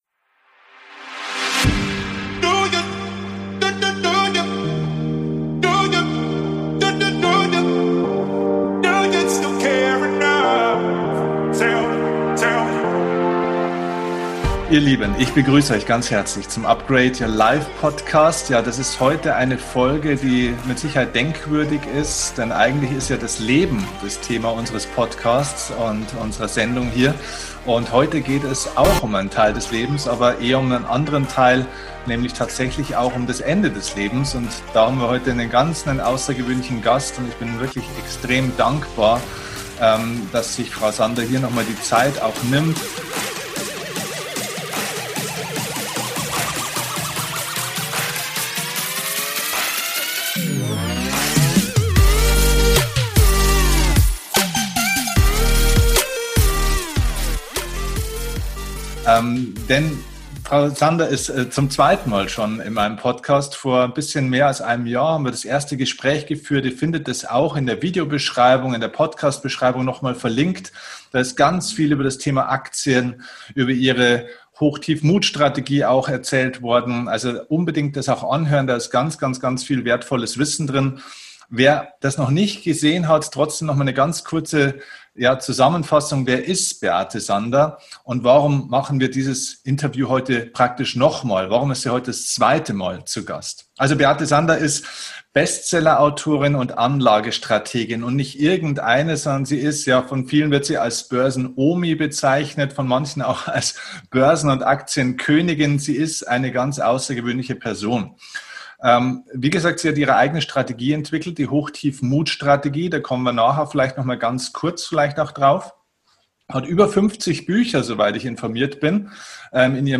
Das ist das zweite Interview, das ich mit Beate Sander führen darf. Ich war letztes Jahr bei ihr zu Besuch und habe ihr einige Fragen zu ihrer großen Landschaft gefragt.
Wir haben das Interview in zwei Teile geschnitten.